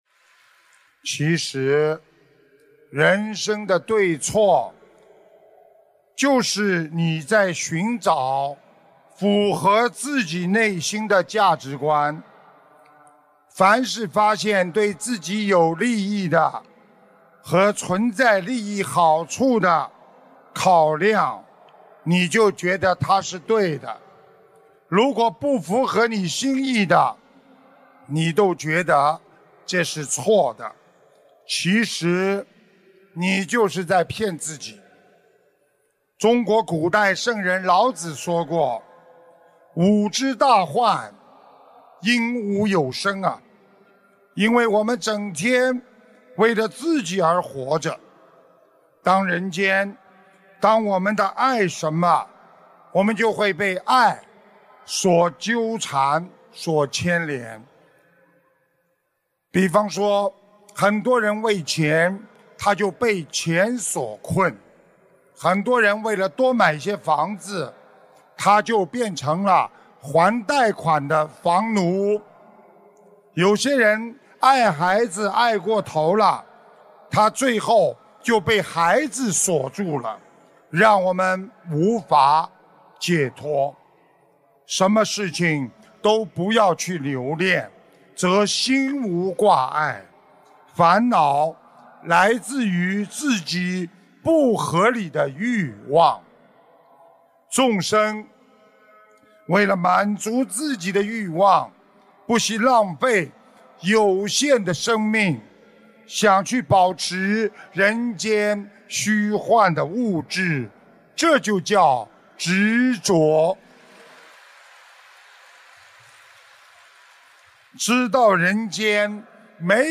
音频：悟性之门常打开！新加坡玄艺综述大型解答会2017年2月18日！